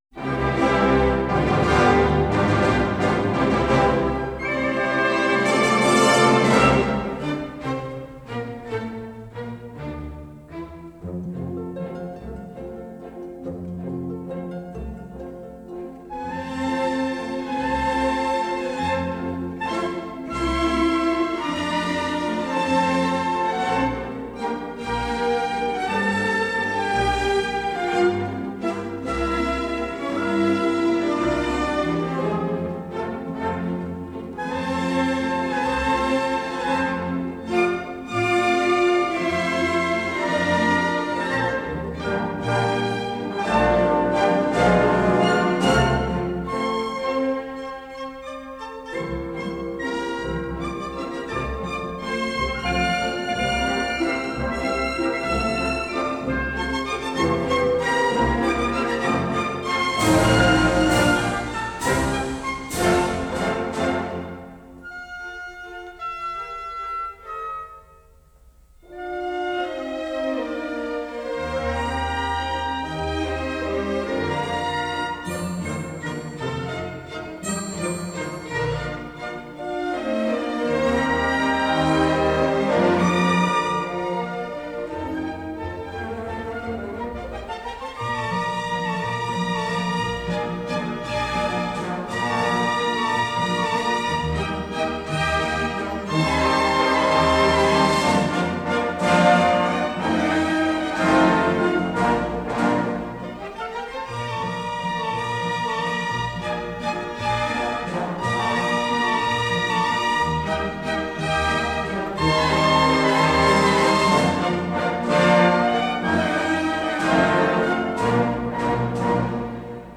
Song Title: Tales from the Vienna Woods – 1868   Genre: Classical   Artist: Orchestra   Composer: Johann Strauss   Album: Classical For The New Age